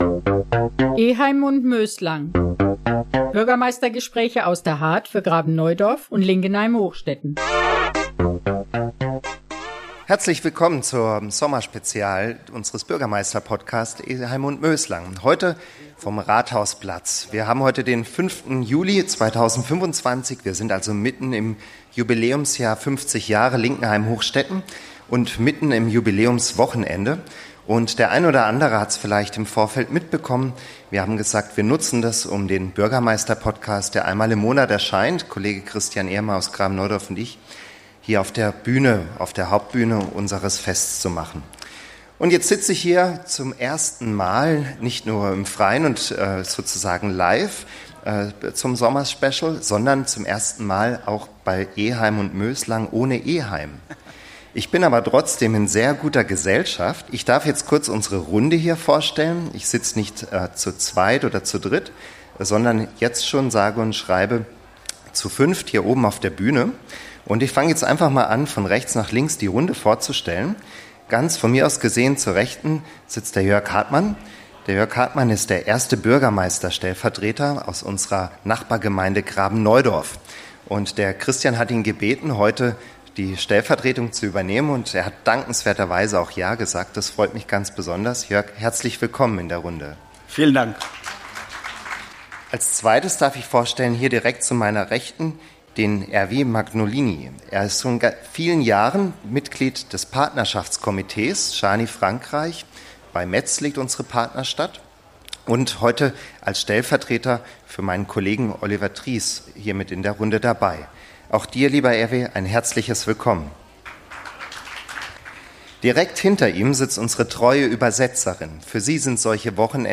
Städtepartnerschaften mit Gröditz Jarny Unsere Gäste berichten Live beim Jubiläumsfest